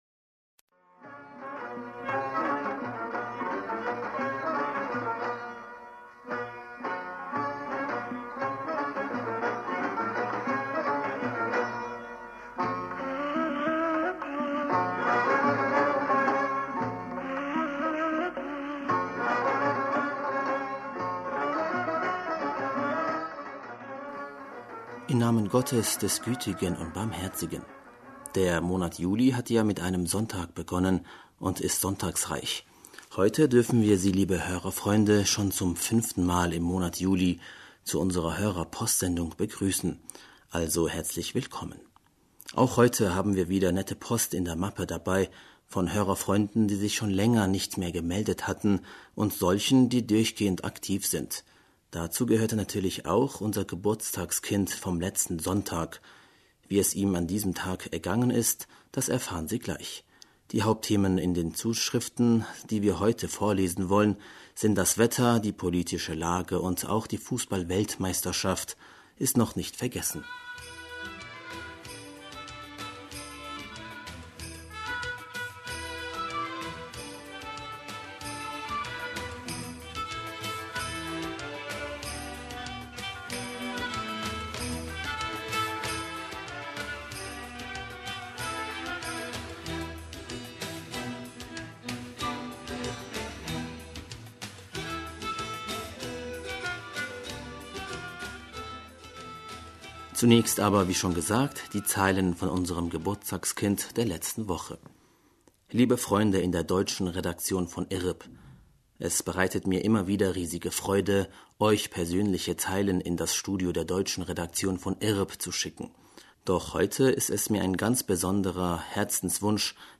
Hörerpostsendung am 29. Juli 2018